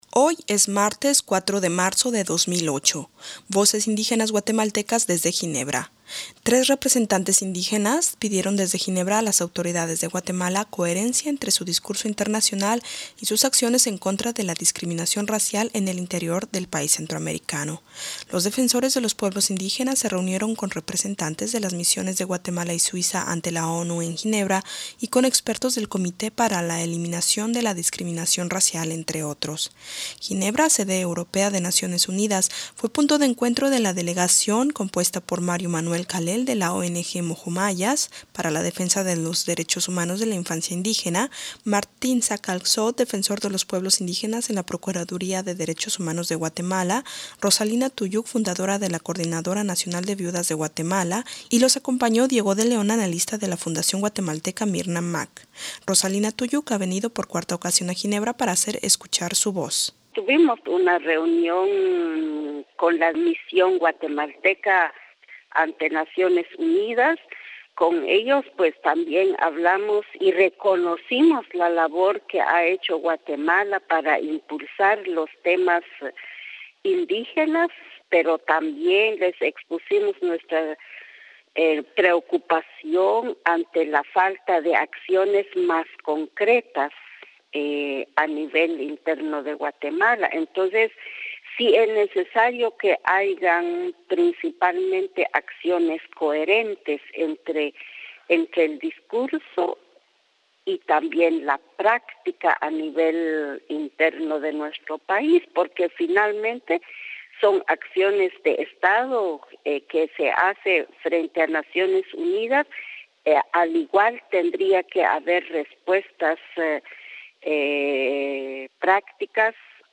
Tres indígenas pidieron desde Ginebra a las autoridades de Guatemala coherencia entre su discurso internacional y sus acciones en contra de la discriminación racial en el interior del país centroamericano.